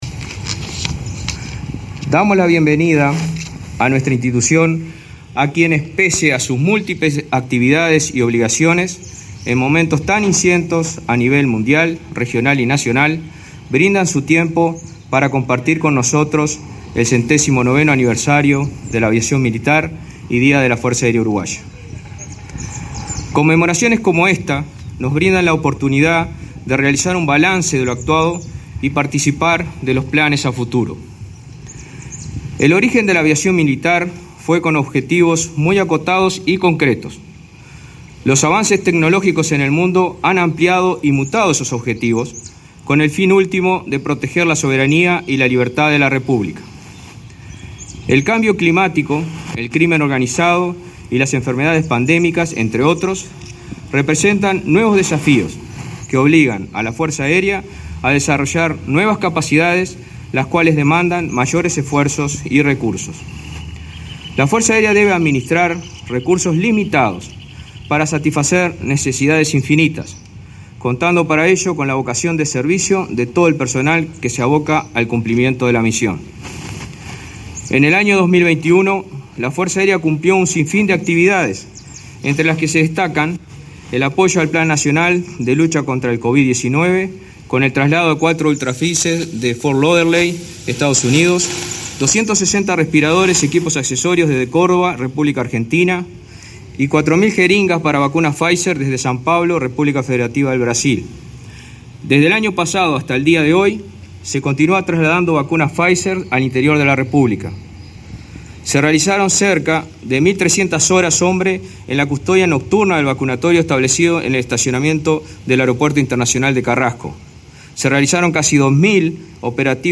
Palabras del comandante de la Fuerza Aérea, Luis De León
El comandante de la Fuerza Aérea, Luis De León, destacó la importancia del 109.° aniversario de la Aviación Militar y Día de la Fuerza Aérea Uruguaya,